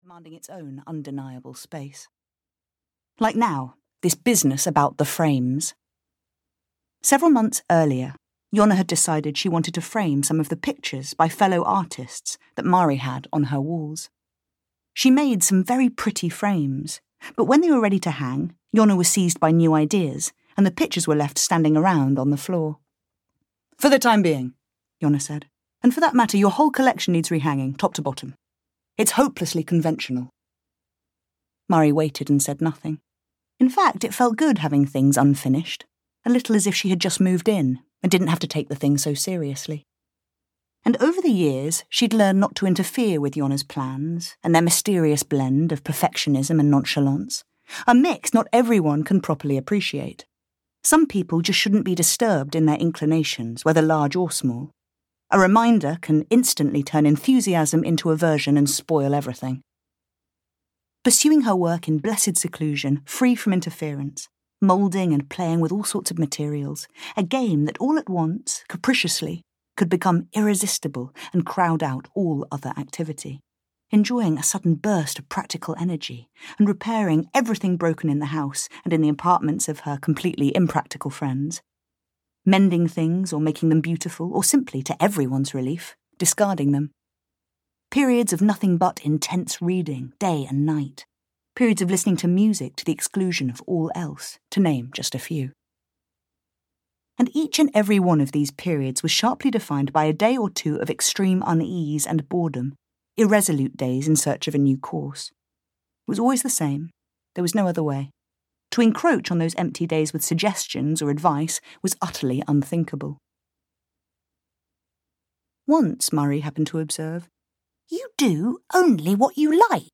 Fair Play (EN) audiokniha
Ukázka z knihy
• InterpretEmma D'Arcy